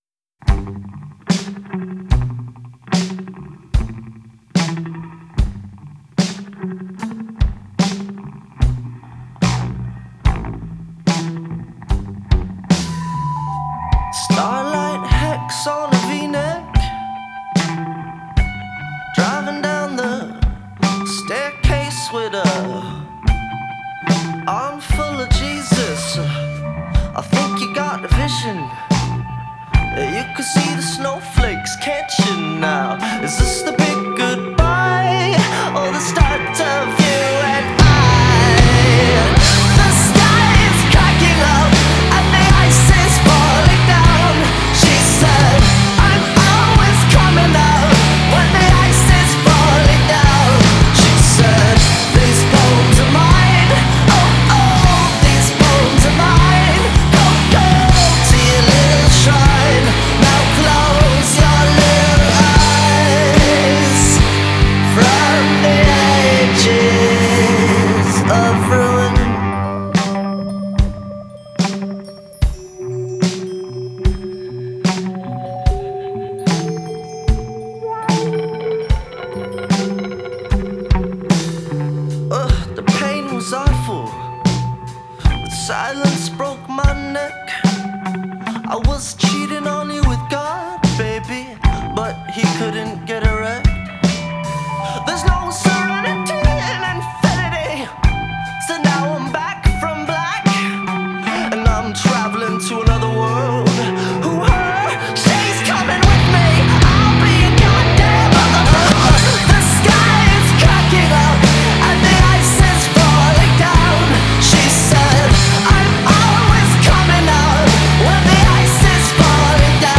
Жанр: Rock, Pop , Indie Rock